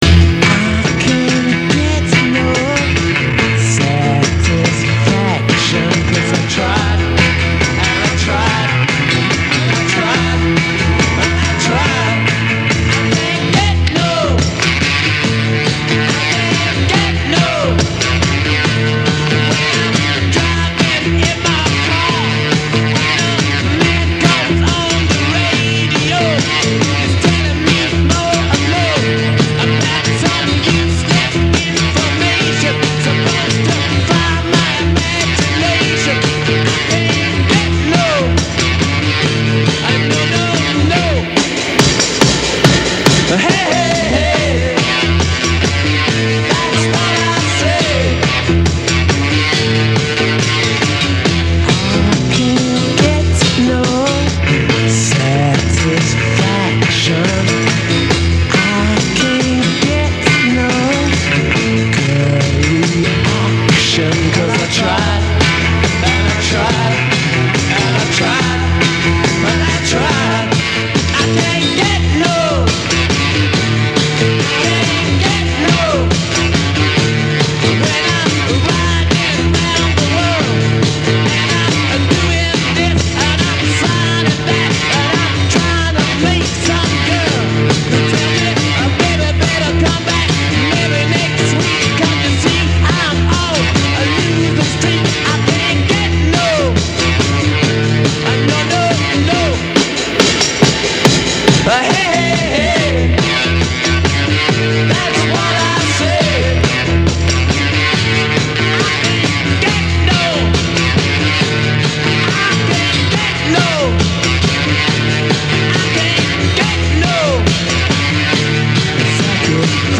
秒速９・５㎝のスピードでオープンテープに録音しています。
ではＳＯＵＮＤの魅力、№２、この時の同録でもう１曲きいてみよう。